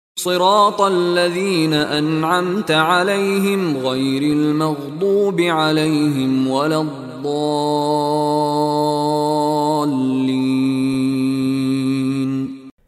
Contoh Bacaan dari Sheikh Mishary Rashid Al-Afasy
Dipanjangkan sebutan huruf Mad dengan 6 Harakat sahaja.